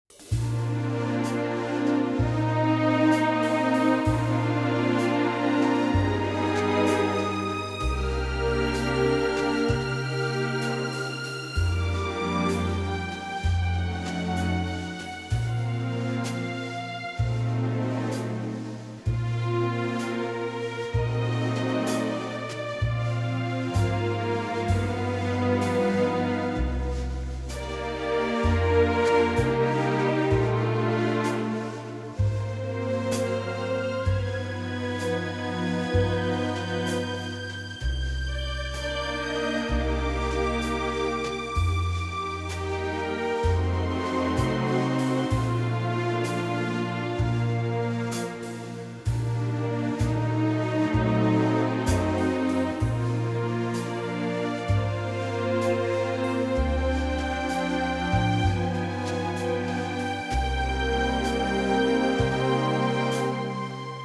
key - Bb - vocal range - F to A